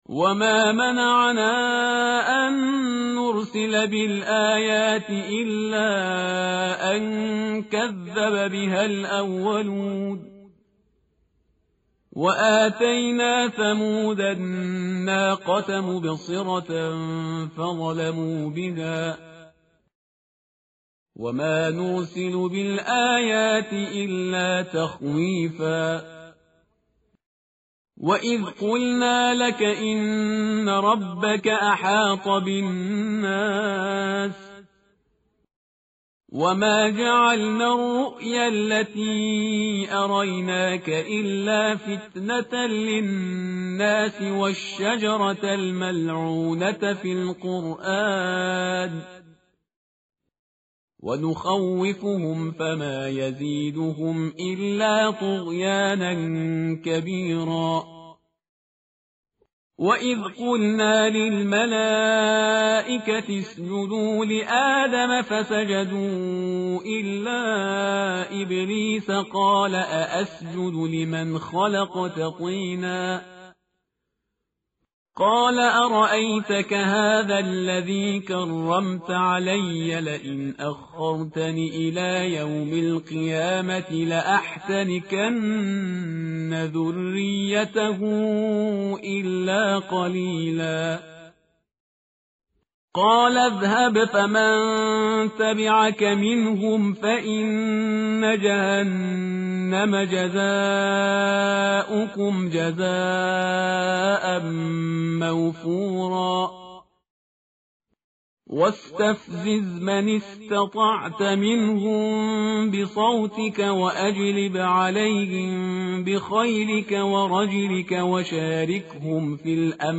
tartil_parhizgar_page_288.mp3